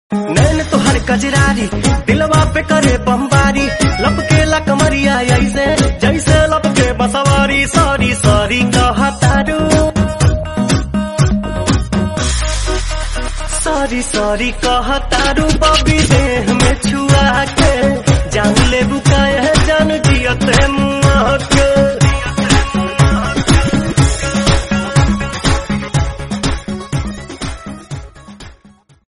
bhojpuri ringtone